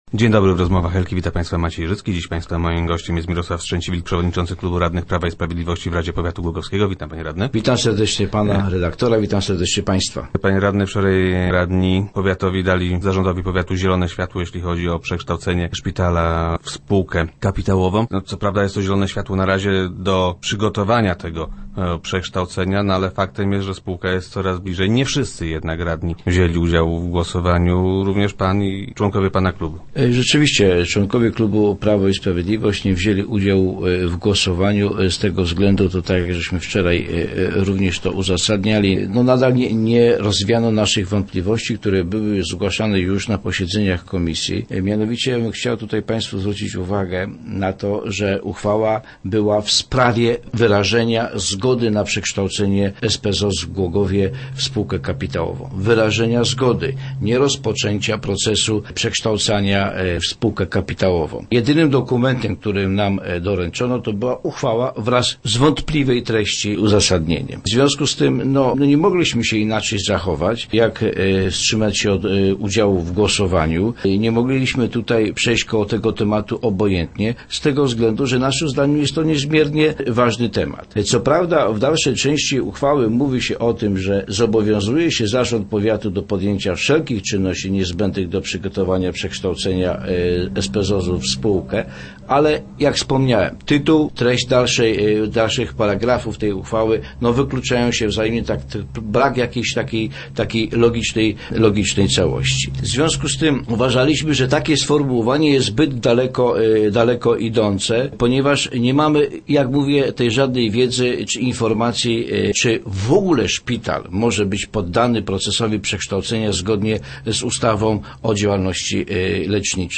- Nie rozwiano naszych wątpliwości – twierdzi Mirosław Strzęciwilk, szef klubu PiS w radzie pow. głogowskiego, który był gościem Rozmów Elki.
Nie mogliśmy więc inaczej się zachować - mówił na radiowej antenie radny Strzęciwilk.